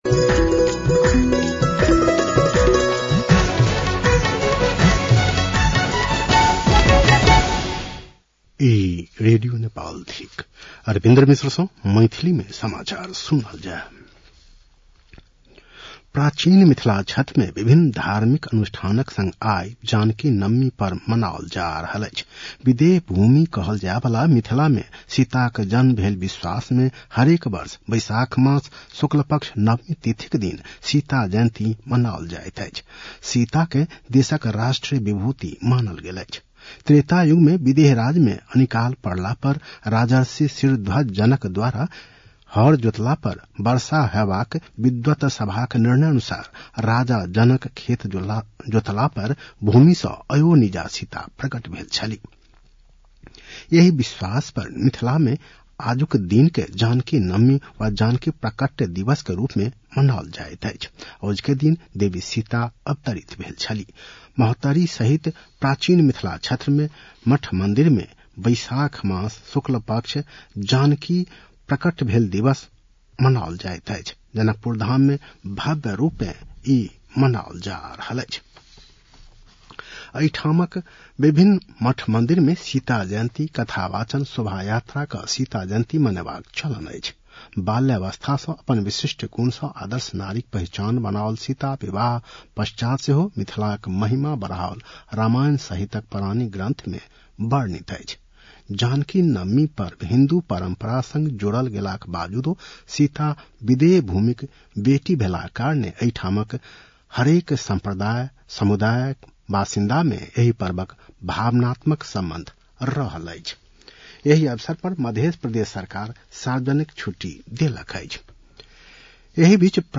मैथिली भाषामा समाचार : २३ वैशाख , २०८२